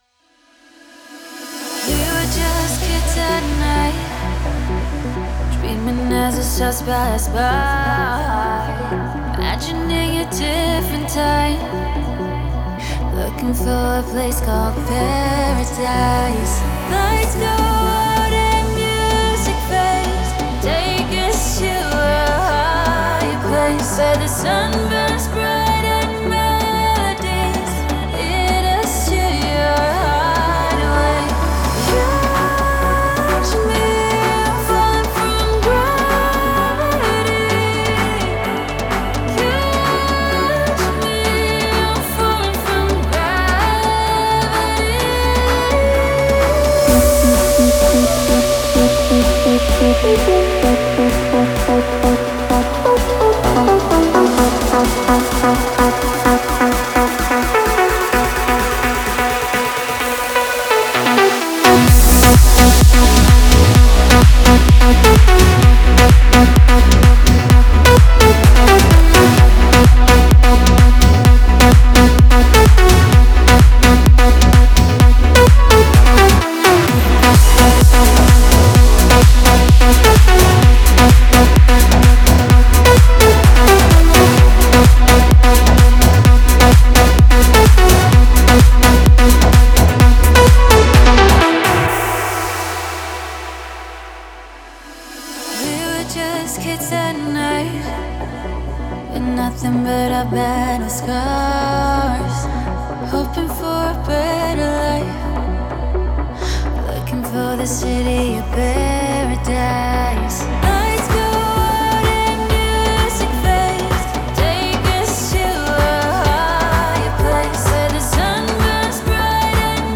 энергичная электронная танцевальная композиция